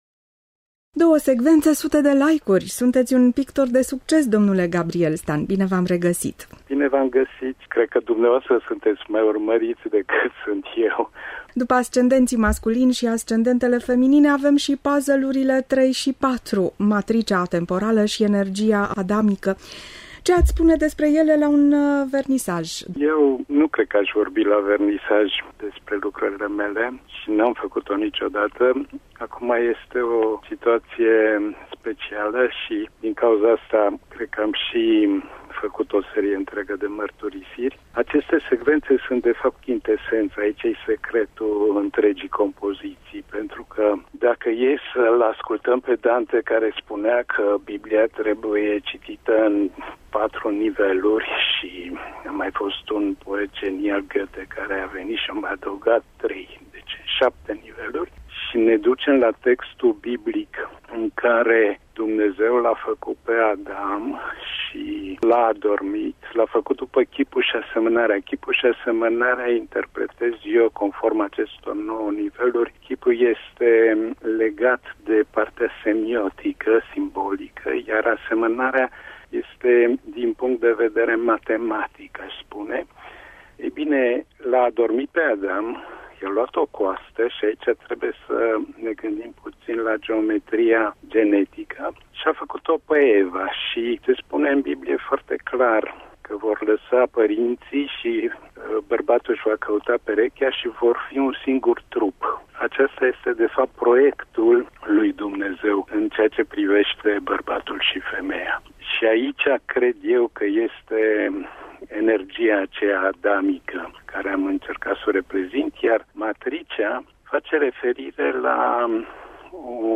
Transcriere audio